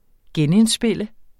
Udtale [ ˈgεn- ]